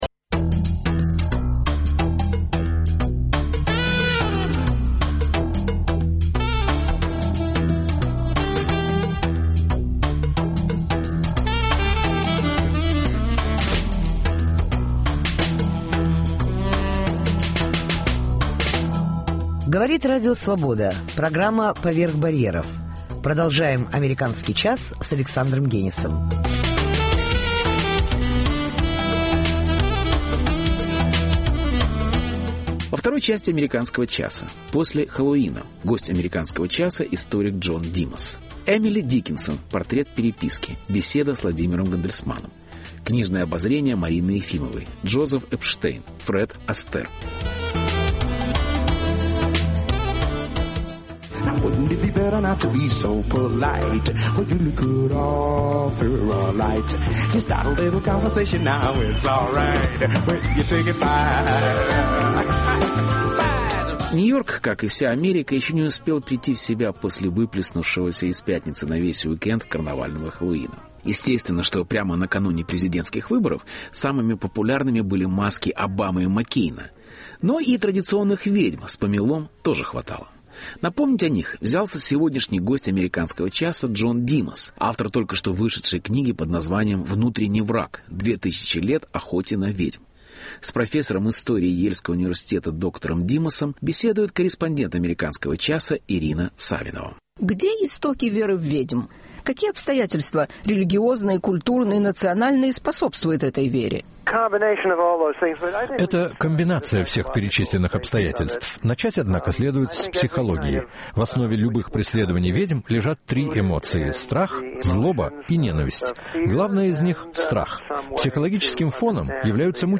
Интервью. После Хеллоуина.